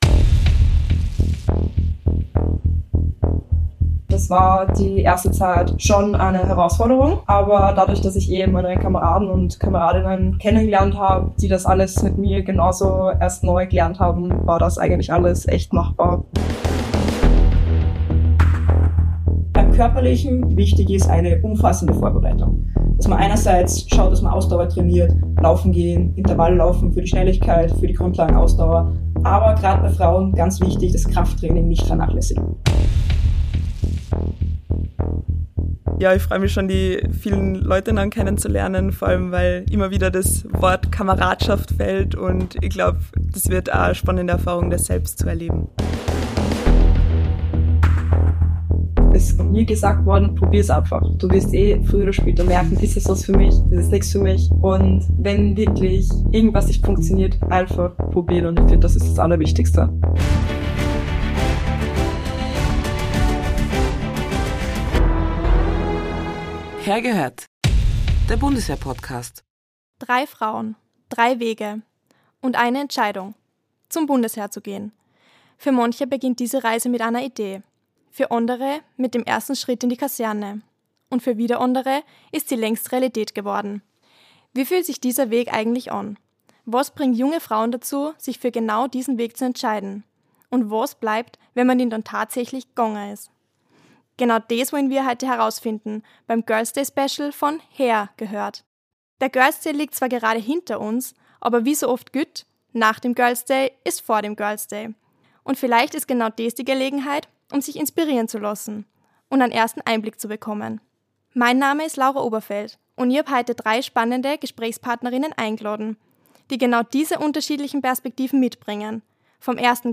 Hinweis vorab: Bei der Aufnahme dieser Folge gab es leider ein technisches Problem, daher entspricht die Tonqualität nicht unserem üblichen Standard.